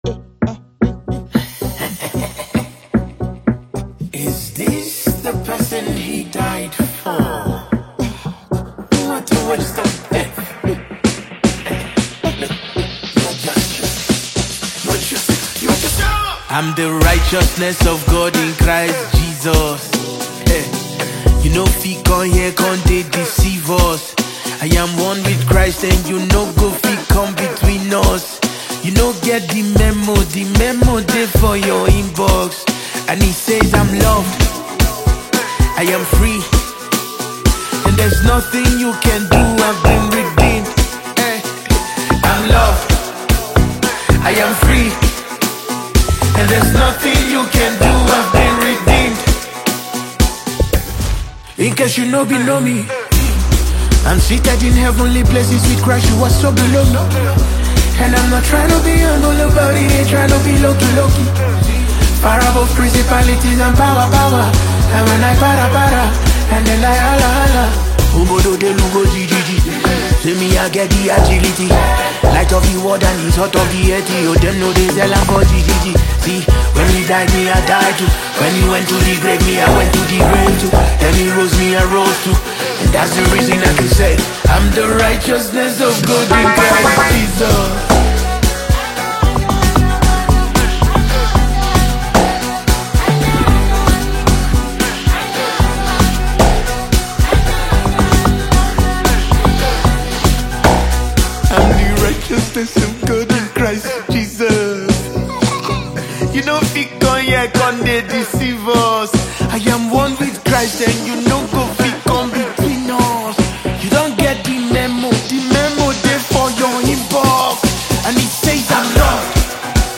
🎶 Genre: Christian & Gospel / Afro-Gospel
🎼 BPM (Tempo): ~113 BPM